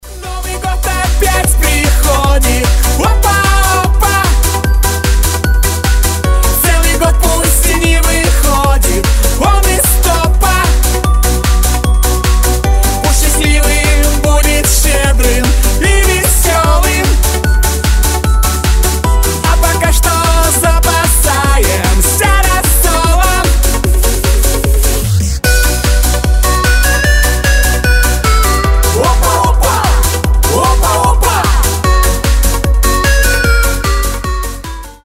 • Качество: 320, Stereo
позитивные
веселые
праздничные
евродэнс